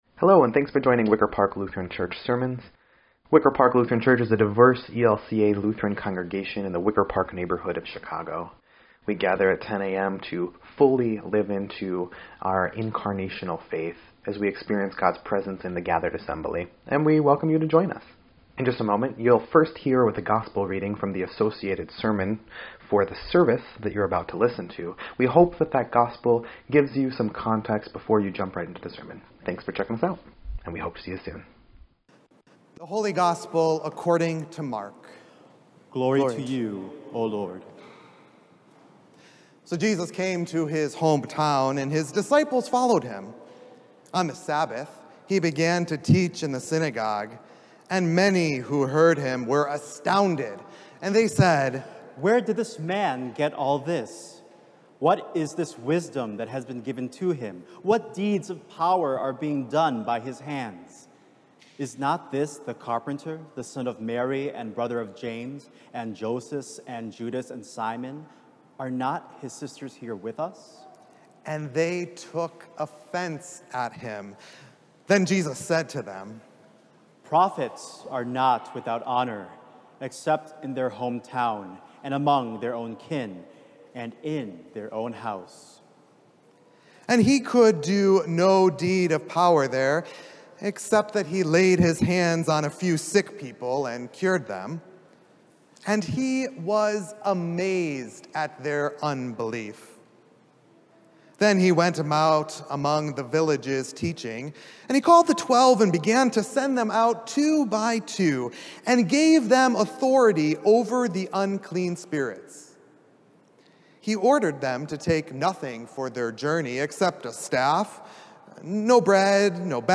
7.4.21-Sermon_EDIT.mp3